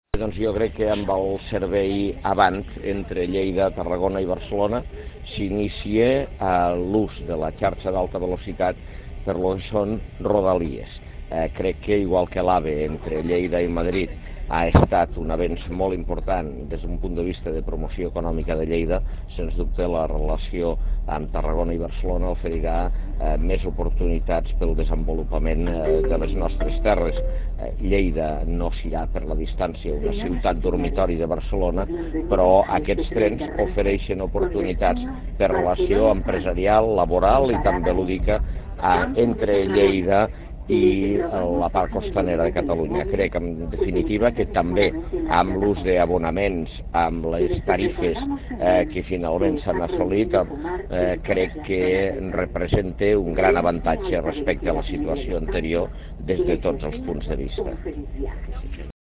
Decarregar imatge original Fitxers relacionats Tall de veu Àngel Ros.
tall-de-veu-angel-ros